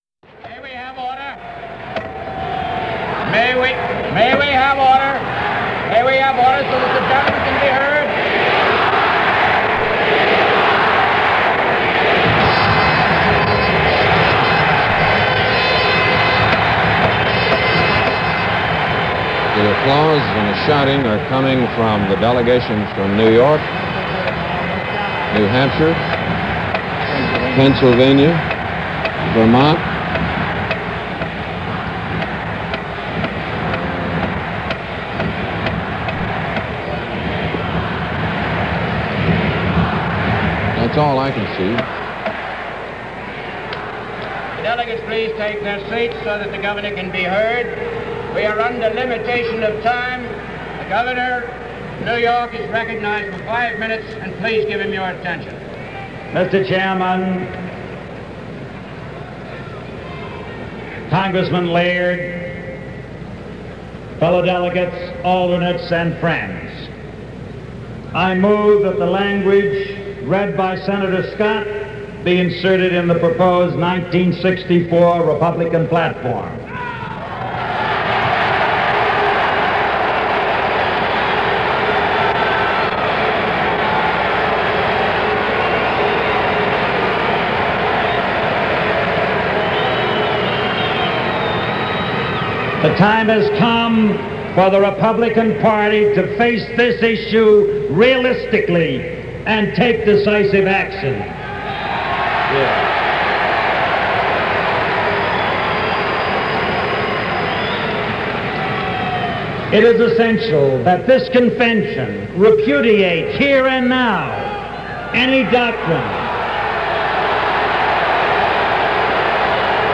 Republican Convention coverage – NBC Radio/TV
Governor Nelson Rockefeller – Warned the party was being overtaken by Right Wing Extremists – got booed for his troubles.